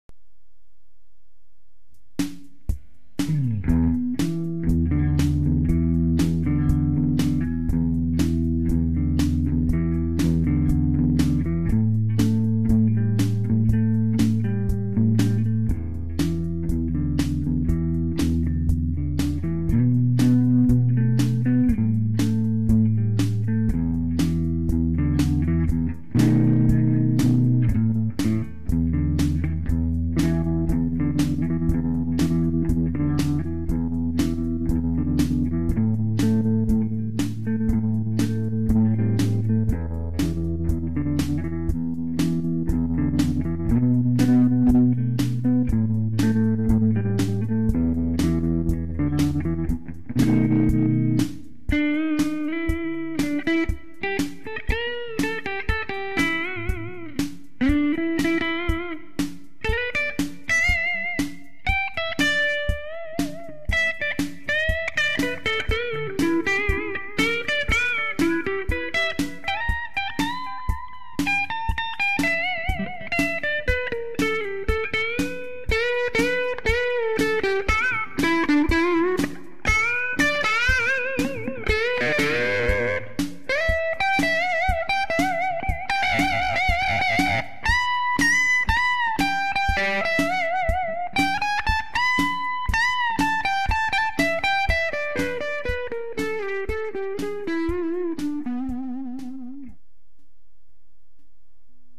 ＰＯＤｘｔにてフェンダーツインリバーブアンプの音作り
イコライザーで持ち上げてみました。
正直、音は割れていますが・・・・・
後半はＴＳ－８０８・オーバードライブモデルかけたりしていますが。
常にリバーブはＯＮ、途中トレモロ、入れたりもしています。
使用ギターはギブソンのレスポールスタンダートです。
Ｆ・ＴＷＲモデル+ギブソンレスポール
ギター演奏がヘボかったりします。
迷いながら弾いていますわ。